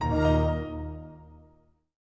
На этой странице собраны классические звуки Windows XP, которые стали символом эпохи.
Звук завершения сеанса Windows XP